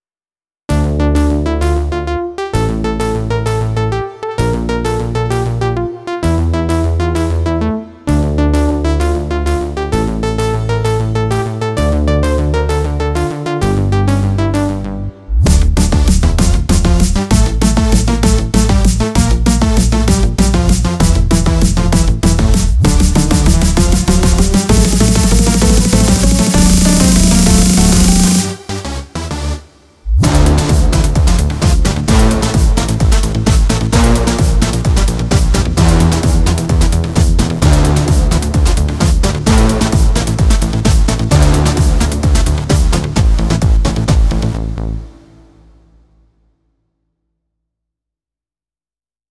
הופה הופה, תוצאות טובות, אתה על ימאהה אם הבנתי נכון?
באמת היו שם טיפה’לה בעיות באקורדים…